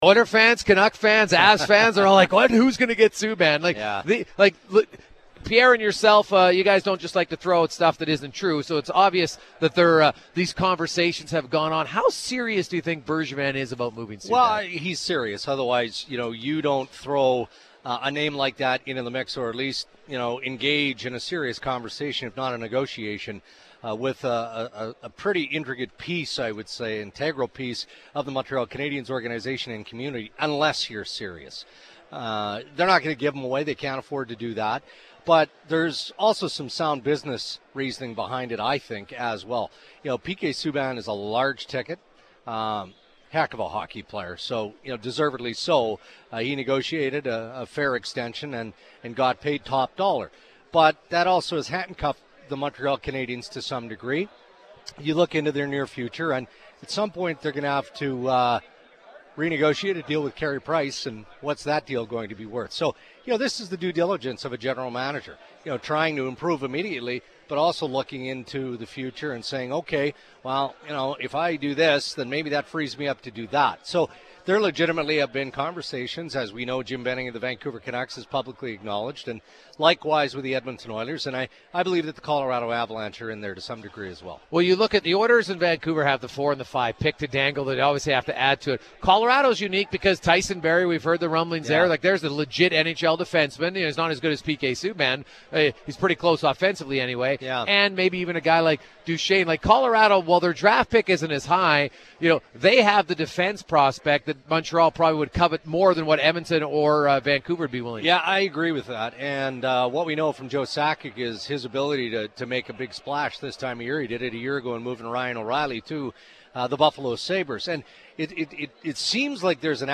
June 23, 2016 – LIVE from the 2016 NHL Entry Draft – Courtesy of Auto City Edmonton